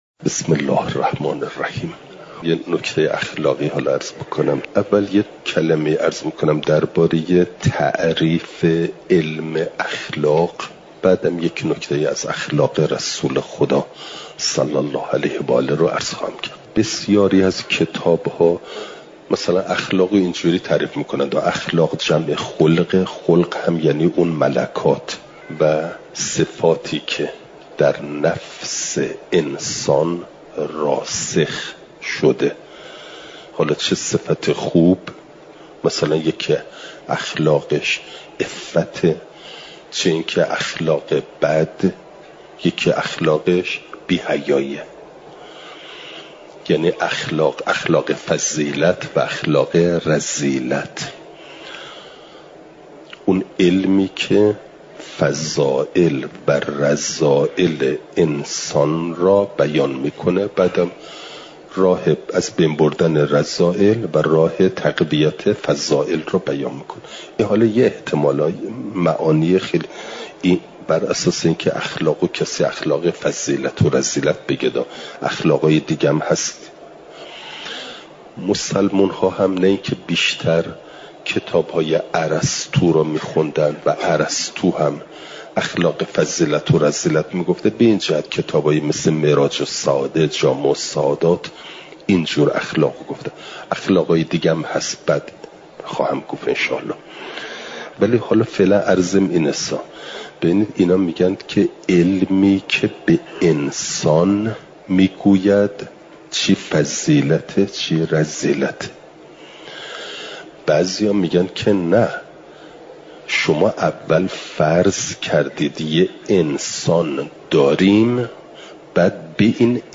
چهارشنبه ۲۸ شهریورماه ۱۴۰۳، حرم مطهر حضرت معصومه سلام ﷲ علیها